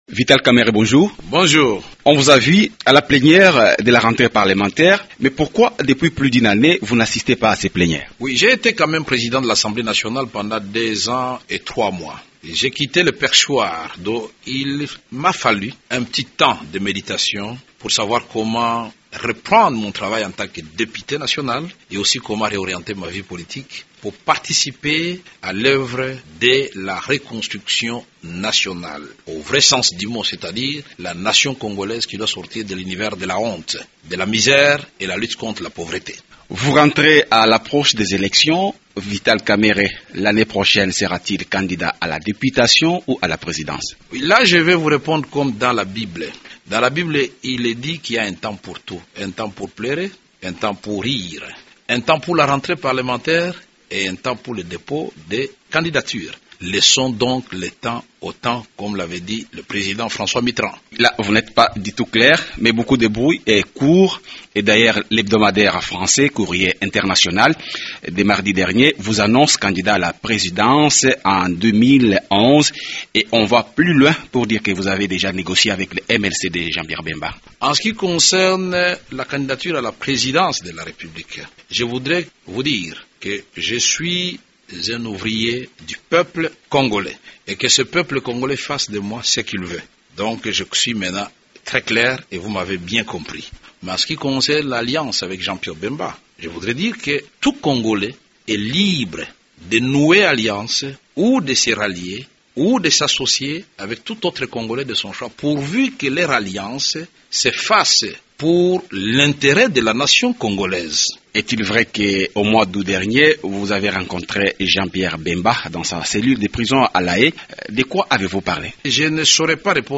Radio Okapi reçoit Vital Kamerhe, président honoraire de l’assemblée Nationale.